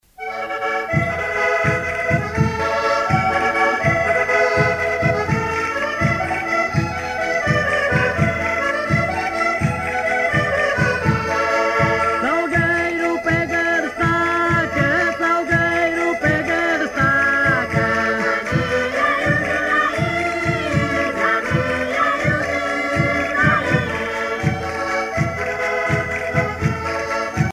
danse : vira (Portugal)
Grupo folclorico da Casa do Concelho de Arcos de Valdevez
Pièce musicale éditée